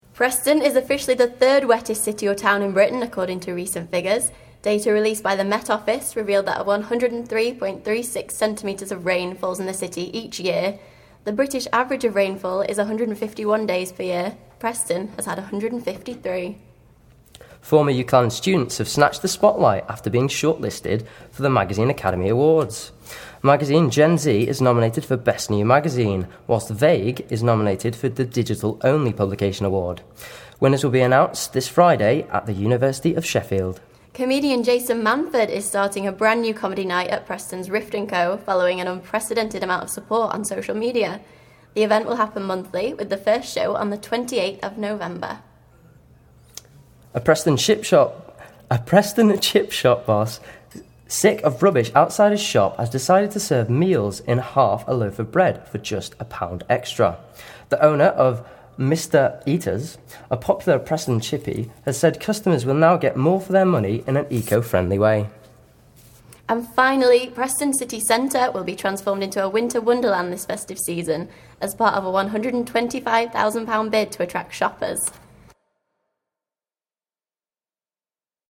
20/10/14 Bulletin - The Week in Review